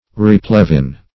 Replevin - definition of Replevin - synonyms, pronunciation, spelling from Free Dictionary
Replevin \Re*plev"in\, v. t. (Law)